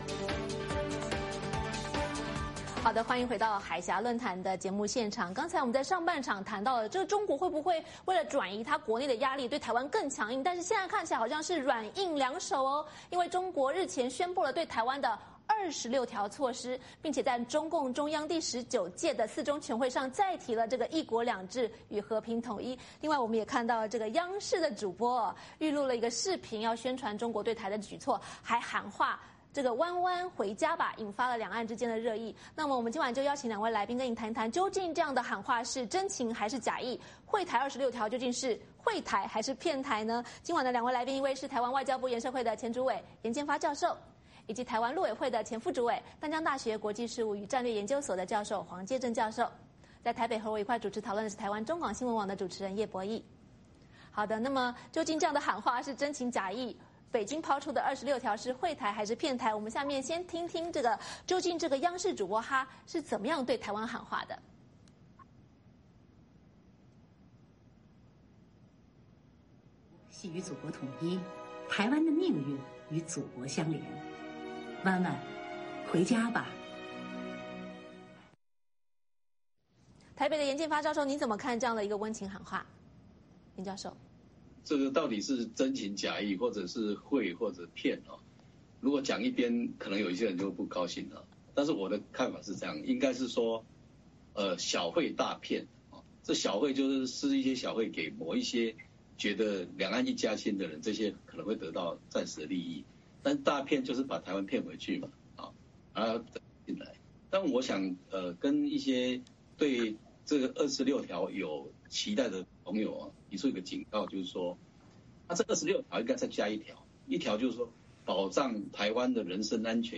北京抛出对台《26条》究竟是惠台还是骗台？习近平对台是否正在施展“和战两手”?《海峡论谈》邀请前台湾陆委会副主委、淡江大学国际事务与战略研究所教授黄介正以及前台湾外交部研设会主委颜建发深入分析。